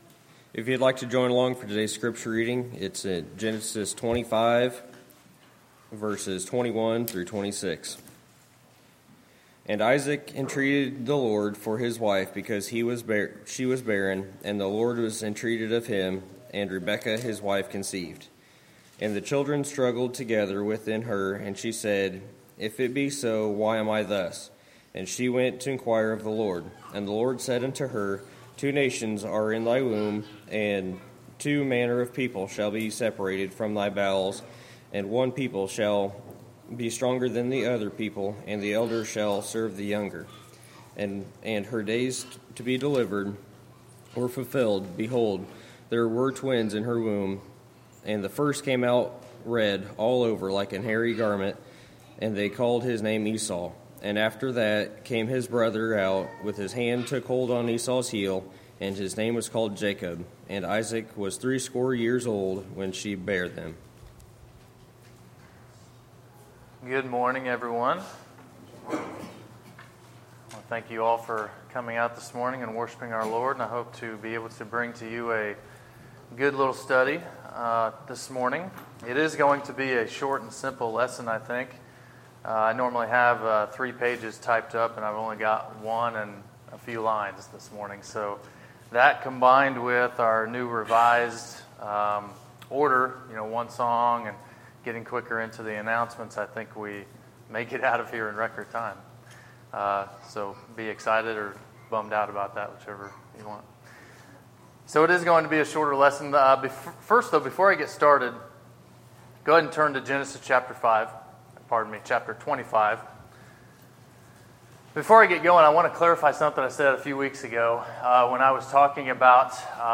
Sermons, August 14, 2016